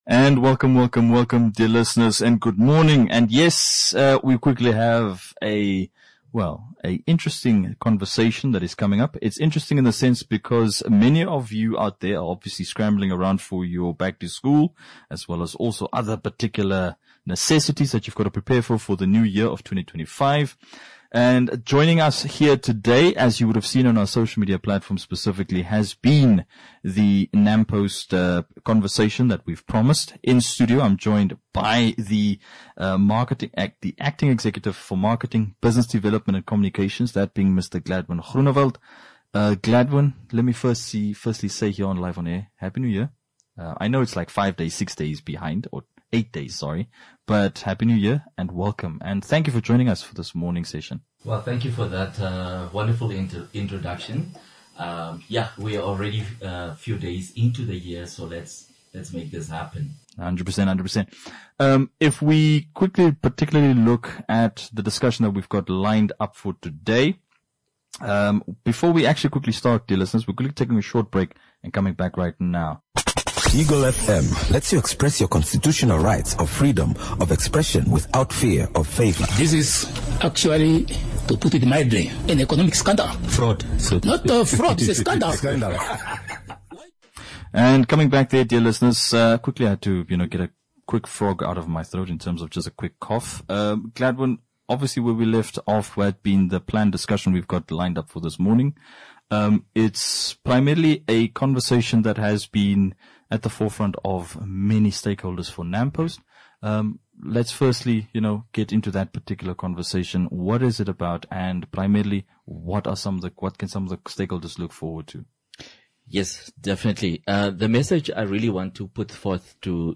INTERVIEW WITH NAMPOST (8 JANUARY 2026)